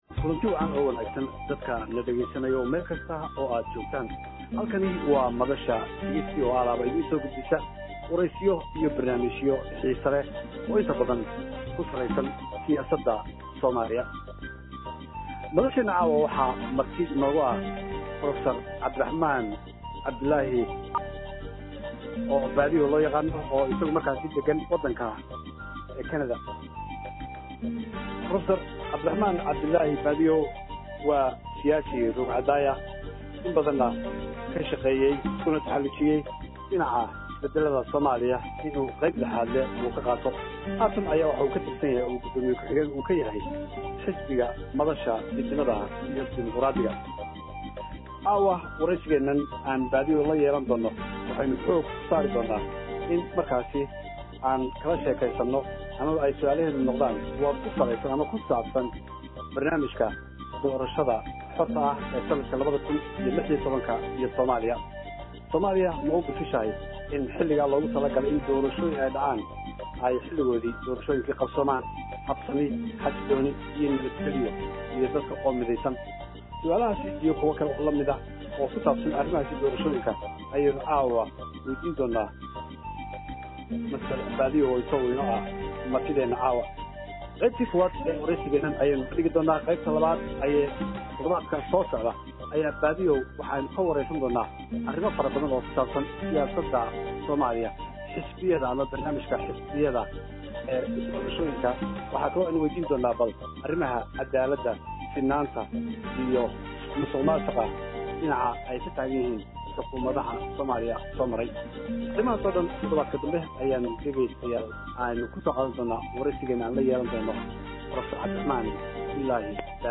Waxaa waraysi xalay lagula yeeshay madasha Lixdanka